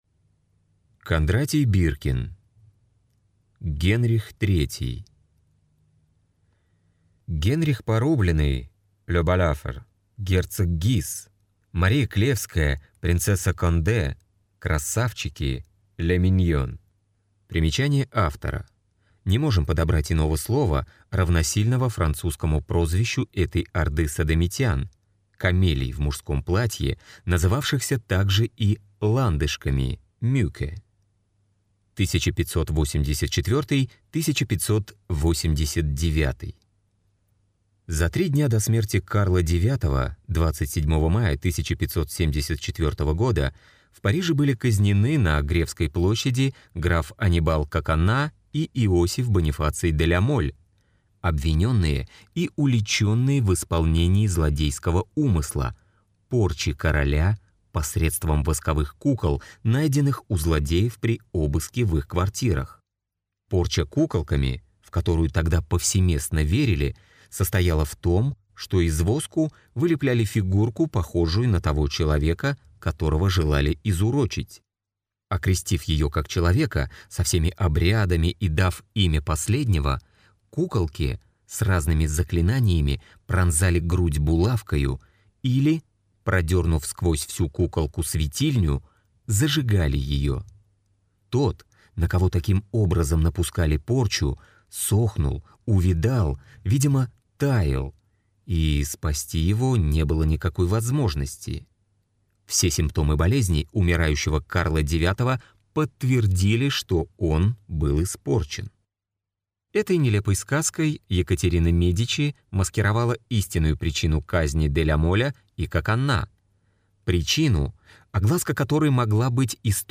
Аудиокнига Генрих III | Библиотека аудиокниг